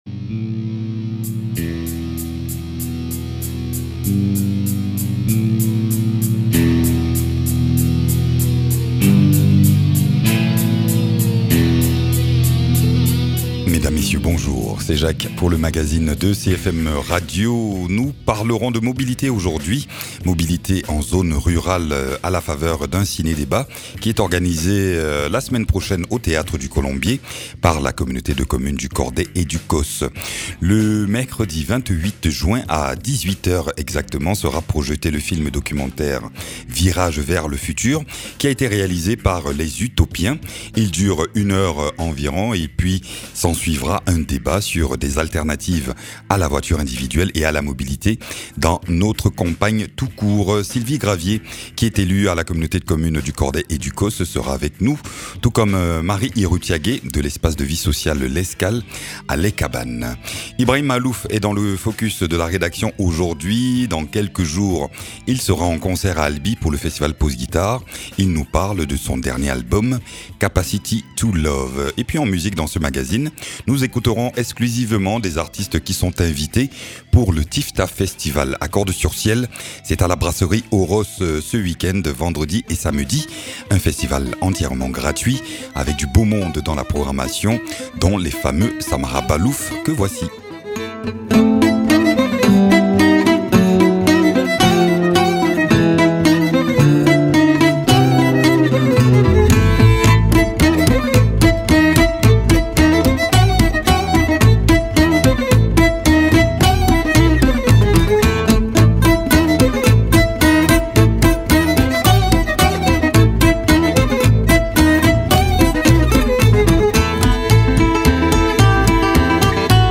Invité(s) : Sylvie Gravier, 3ème vice-présidente de la communauté de communes du Cordais et du Causse en charge du développement durable